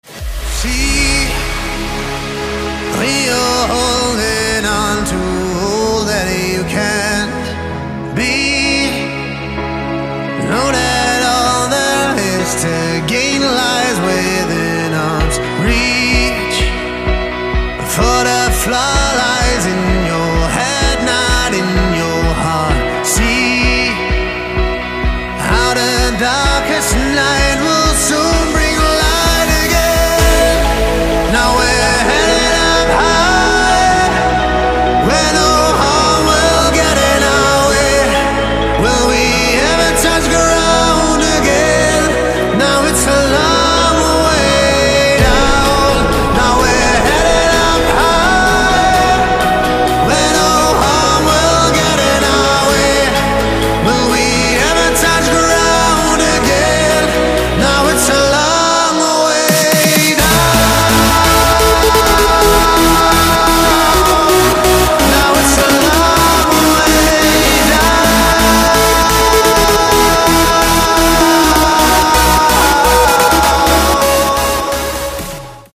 • Качество: 192, Stereo
красивый мужской голос
Electronic
EDM
электронная музыка
нарастающие
клавишные
club
progressive trance
Trance